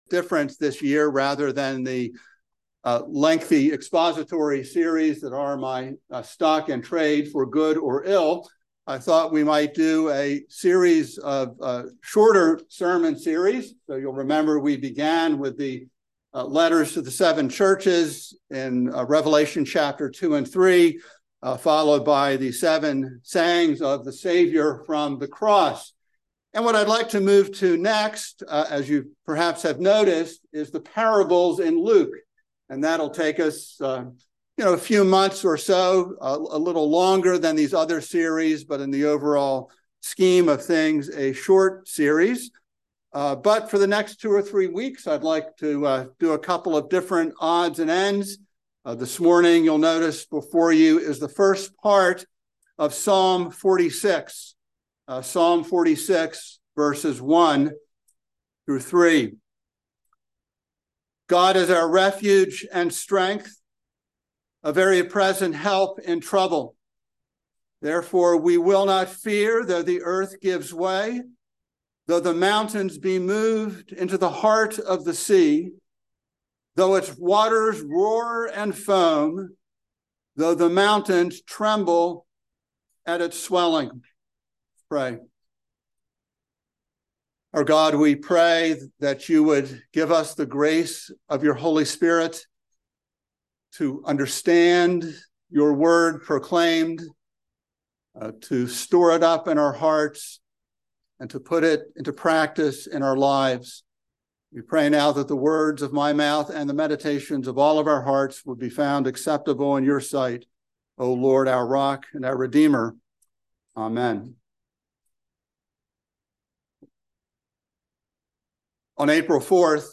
by Trinity Presbyterian Church | Jan 22, 2024 | Sermon